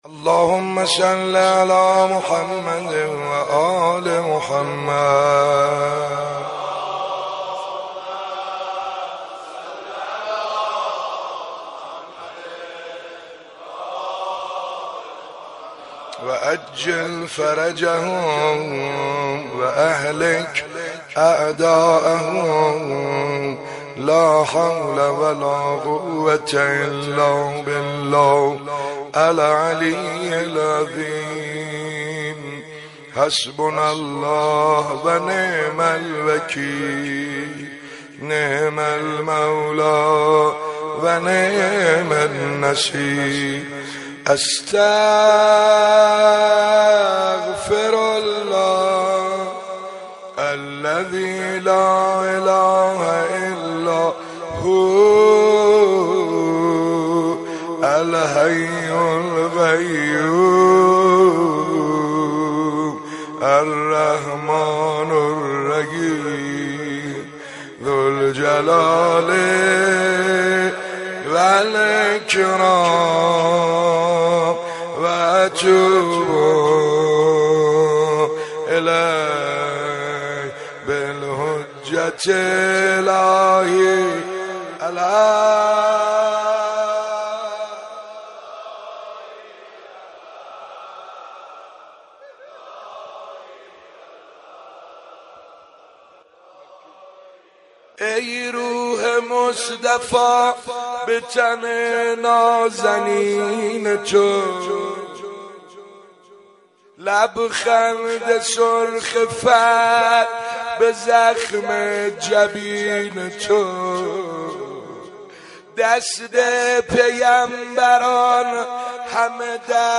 روضه اربعین